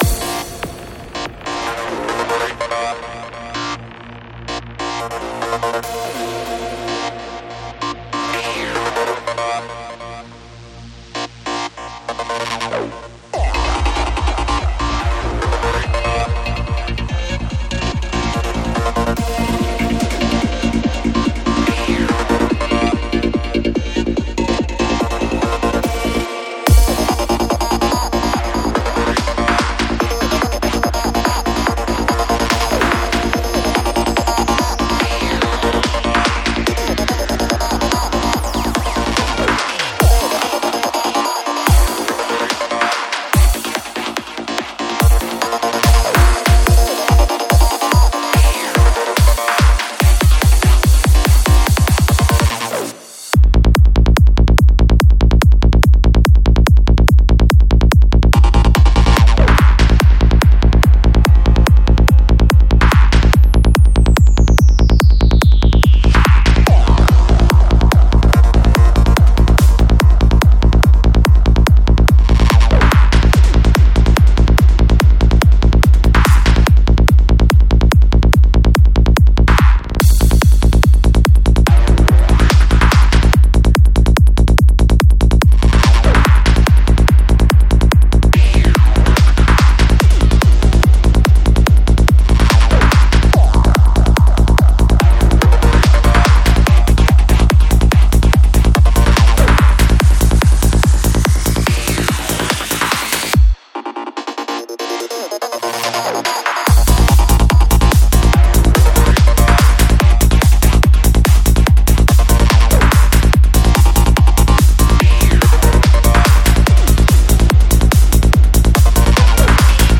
Жанр: Psytrance